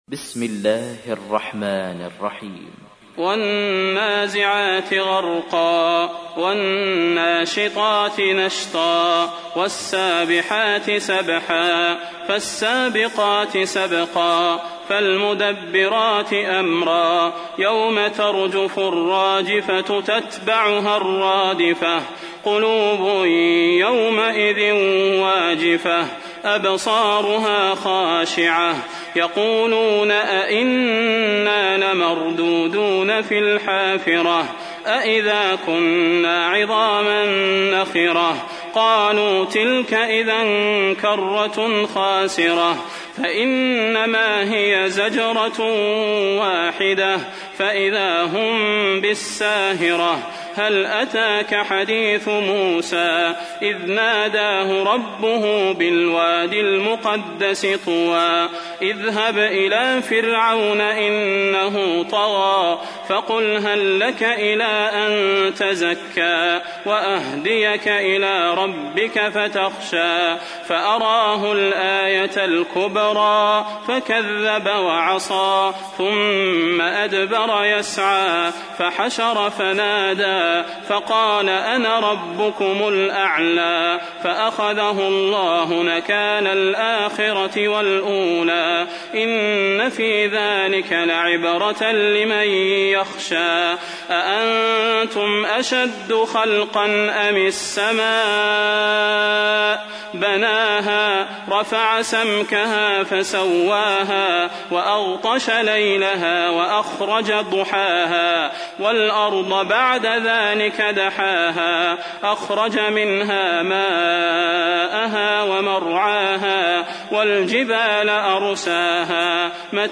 تحميل : 79. سورة النازعات / القارئ صلاح البدير / القرآن الكريم / موقع يا حسين